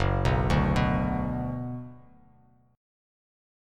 Gbsus4#5 chord